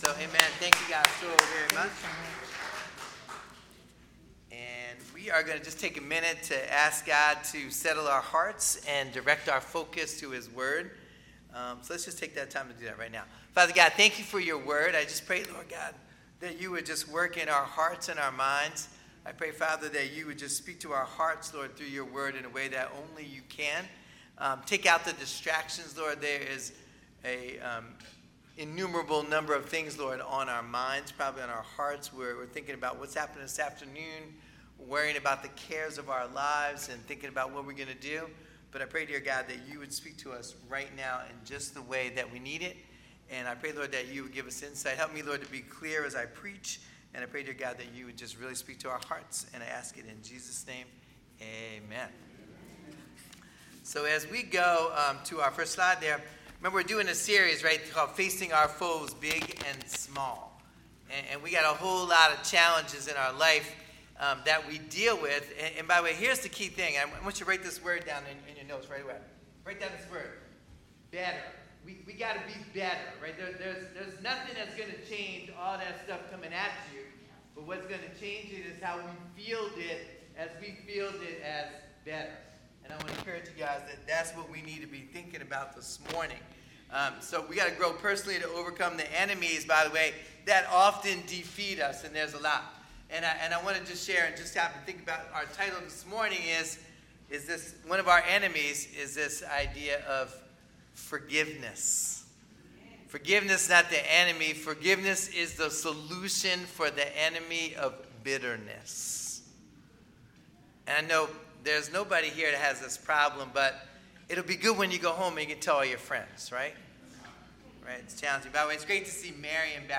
Sermons | First Baptist Church of Willingboro, NJ